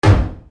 monsterStep1.mp3